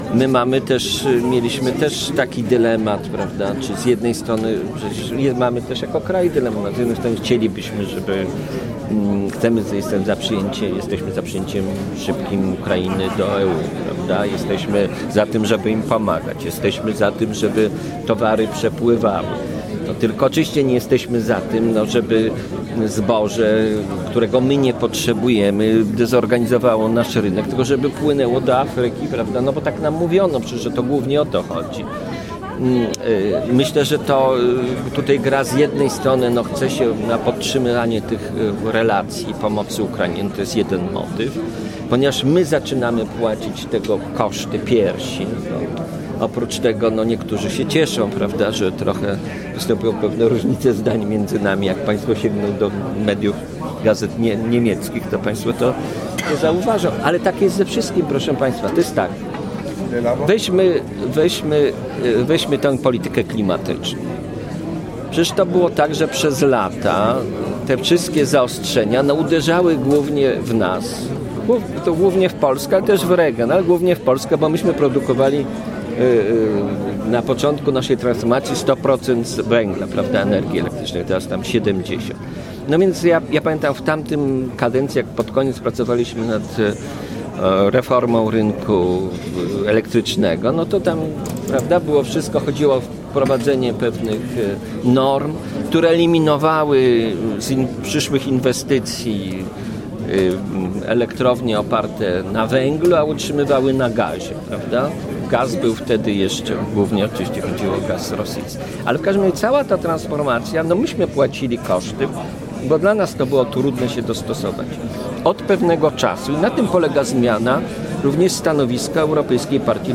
Posłuchaj wypowiedzi prof. Zdzisława Krasnodębskiego, europosła PiS-u: